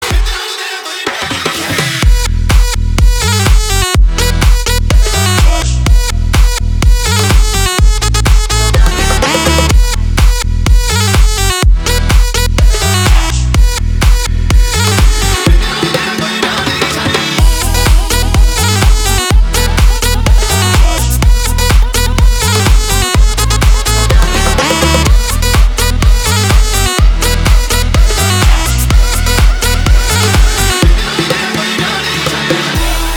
• Качество: 320, Stereo
громкие
deep house
восточные мотивы
энергичные
духовые
Весёлый трек с восточными мотивами.